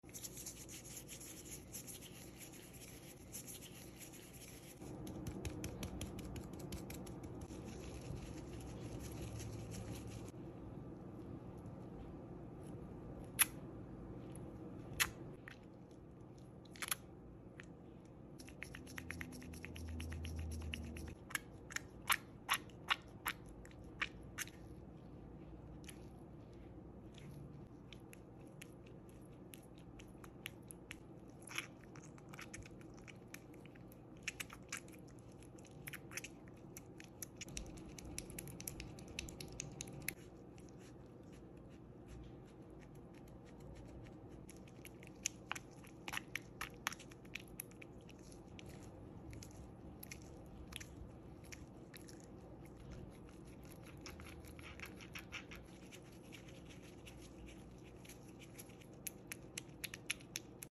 Part 1: Dragon Fruit Soothing Sound Effects Free Download